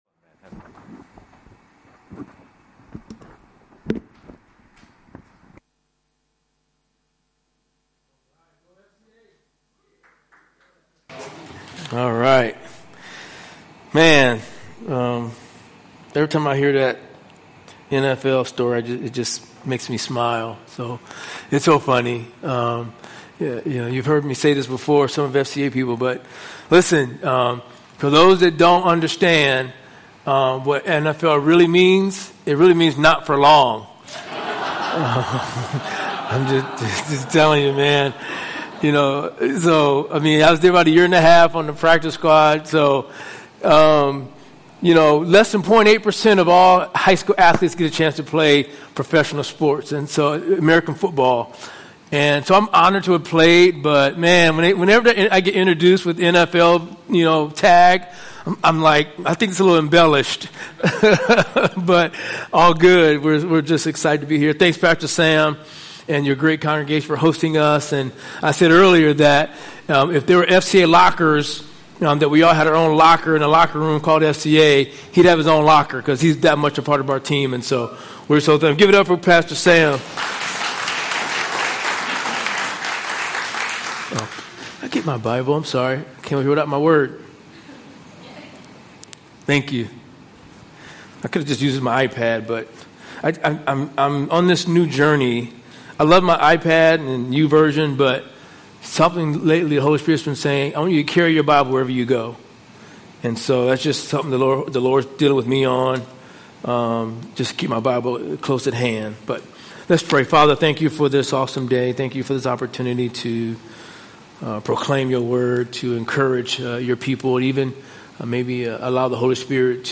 Sermons from Solomon's Porch Hong Kong.